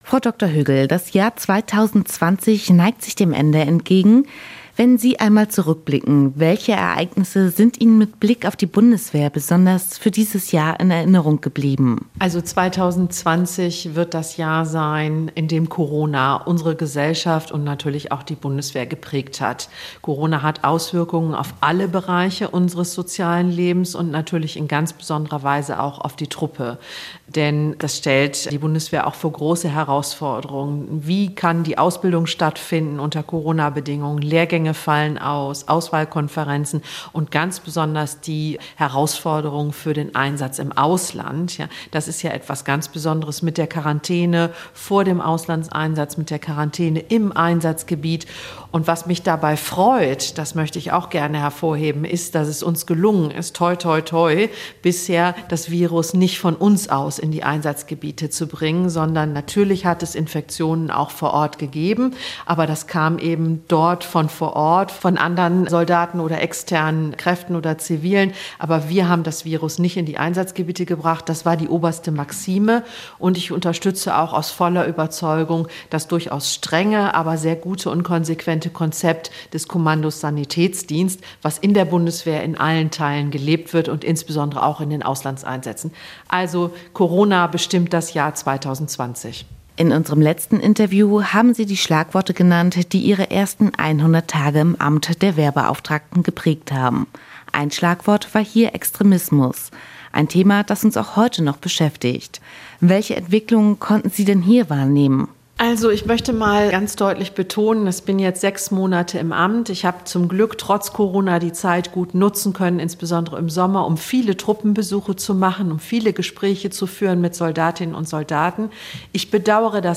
Jahresrückblick 2020 mit der Wehrbeauftragten des Bundestages, Dr. Eva Högl, bei Radio Andernach.